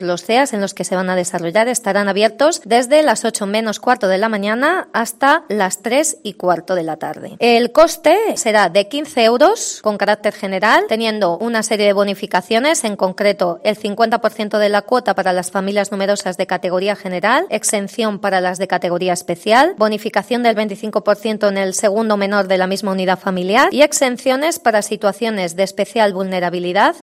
La concejala Myriam Rodríguez explica que hay 442 plazas disponibles para niños entre 3 y 13 años